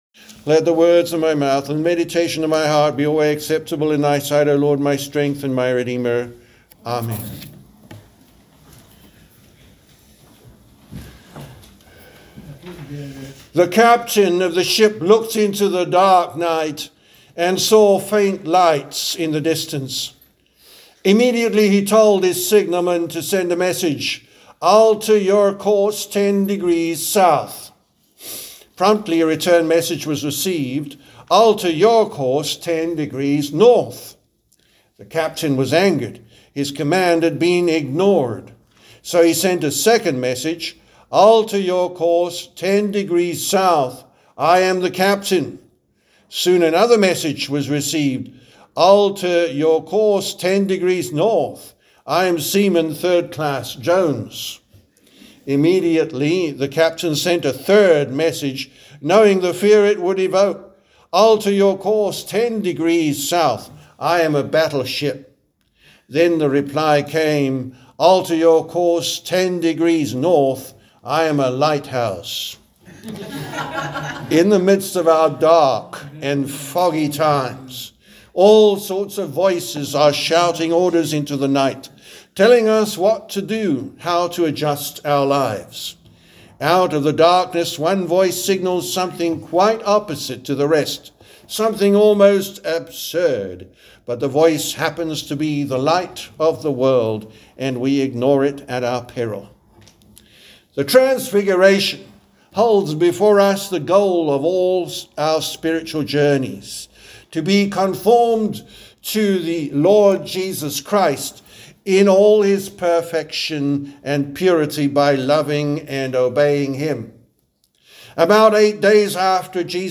The Sermon for Quinquagesima, or the Sunday before Lent, March 2nd, 2025